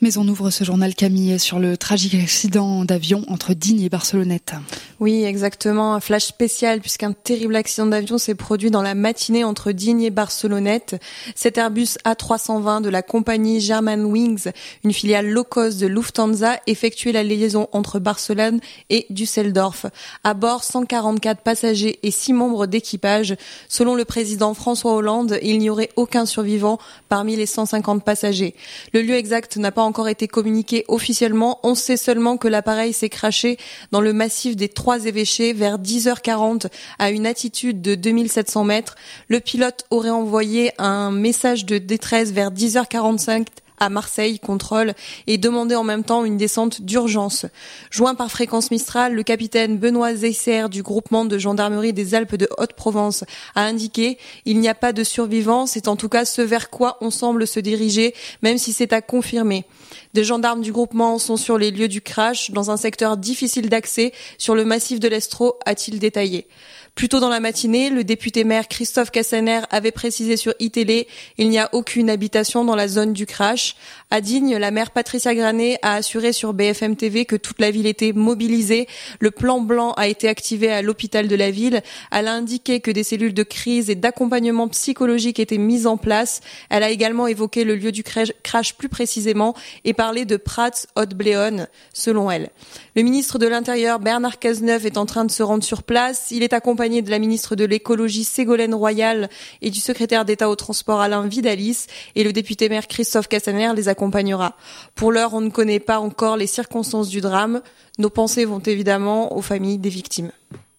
Flash info crash d'avion.mp3 (1.69 Mo)